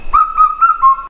四声杜鹃鸟叫声